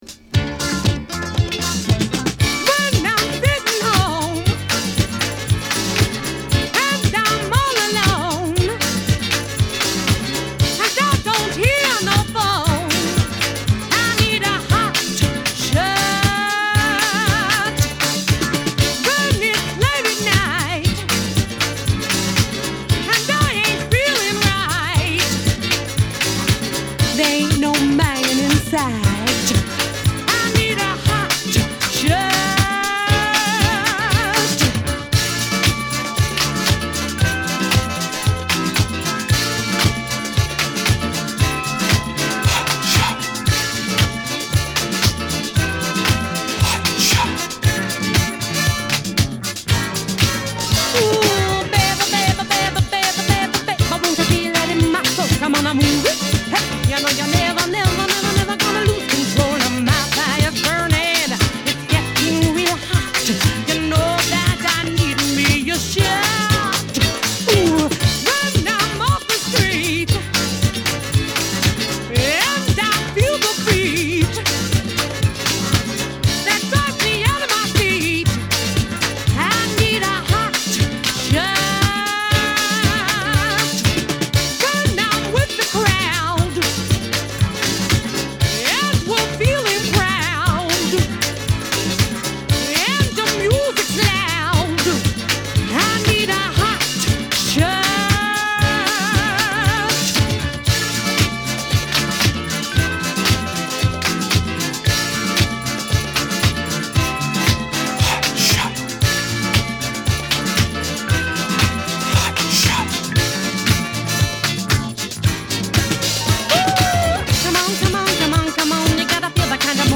mixed as a continuous groove